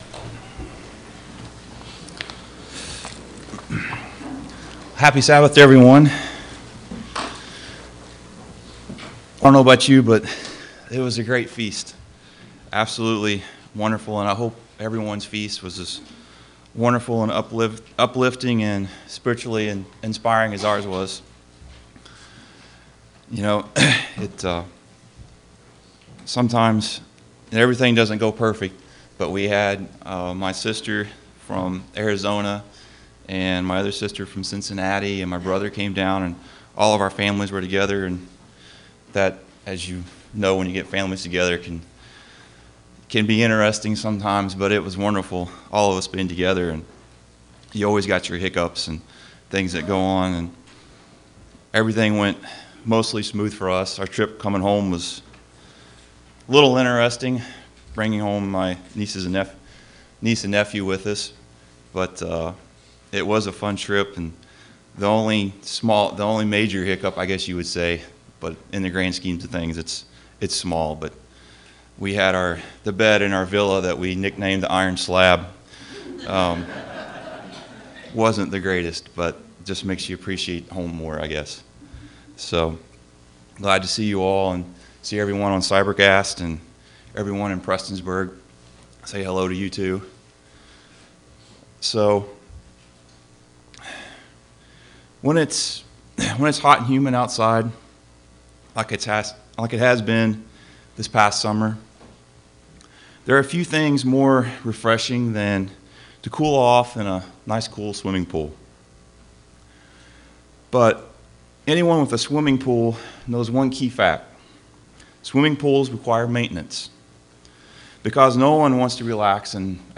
Sermons
Given in Portsmouth, OH Paintsville, KY